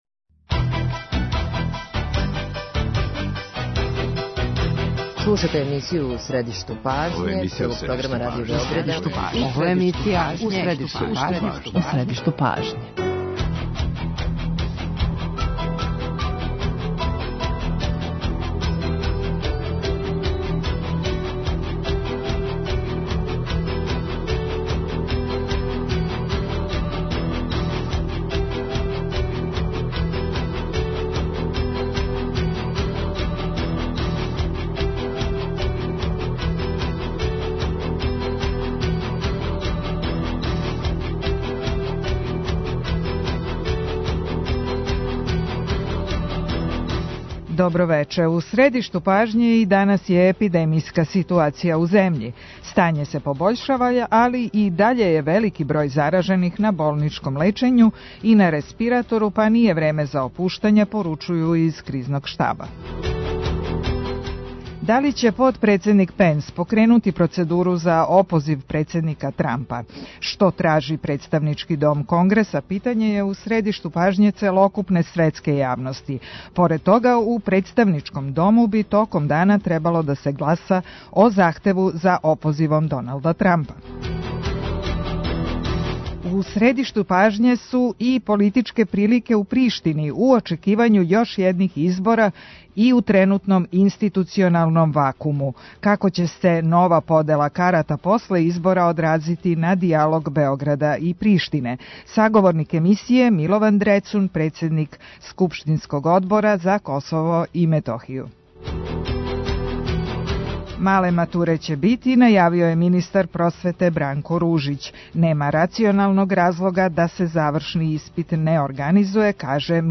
Саговорник емисије је Милован Дрецун, председник скупштинског Одбора за Косово и Метохију.